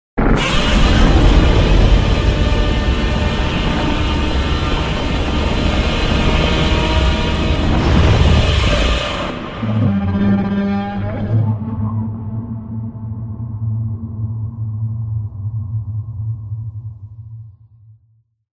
Godzilla 2014 - Roar Sound Effect.mp3